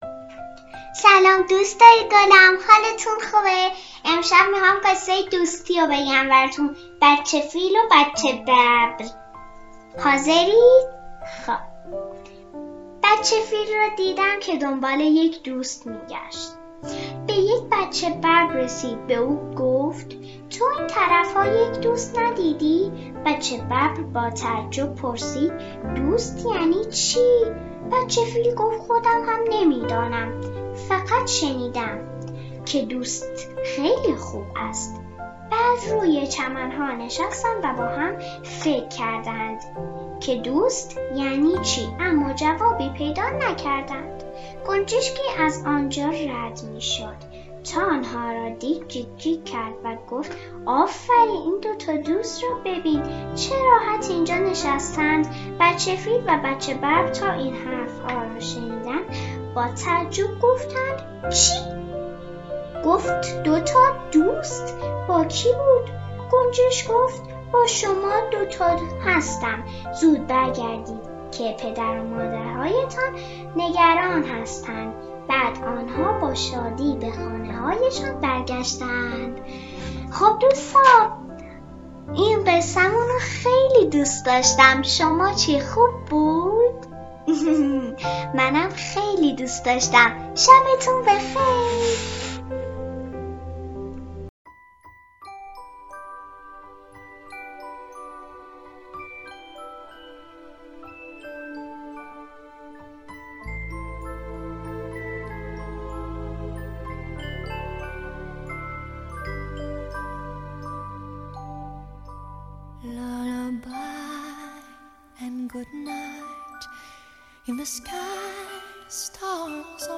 قصه کودکان
قصه صوتی